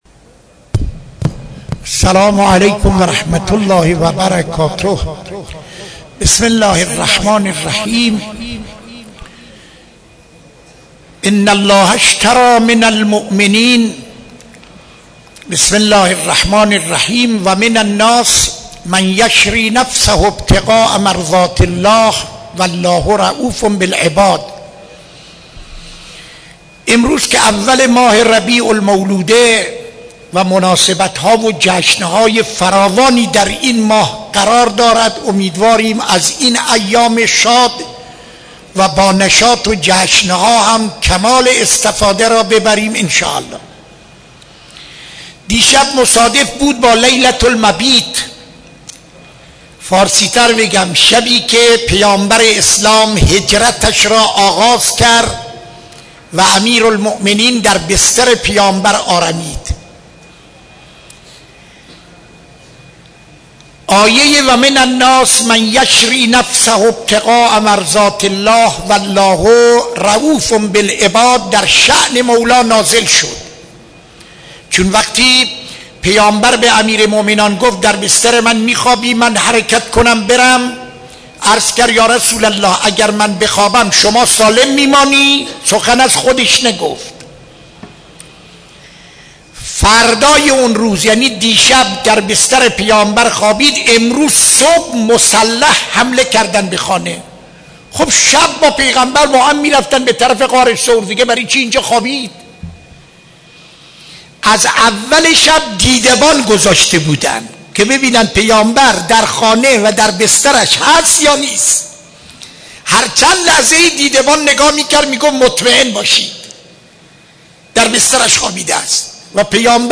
سخنرانی در مسجد دانشگاه (یکشنبه 22-09-94)